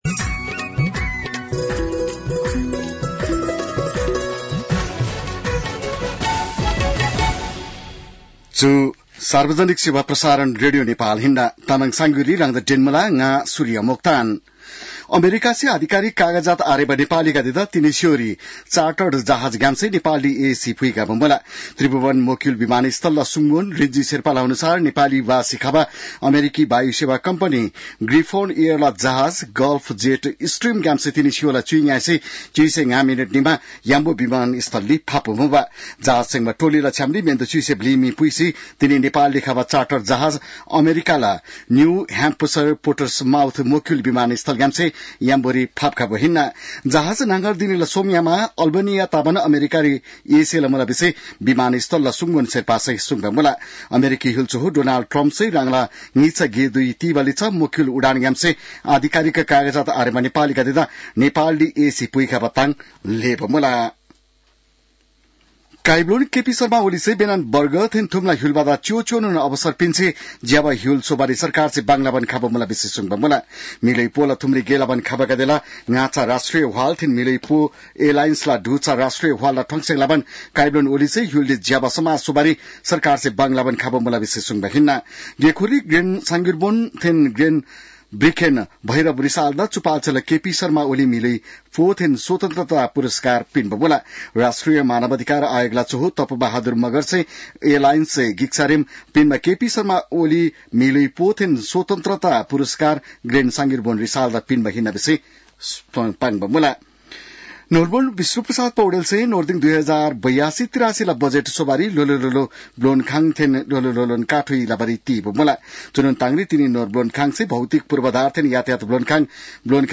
तामाङ भाषाको समाचार : २२ फागुन , २०८१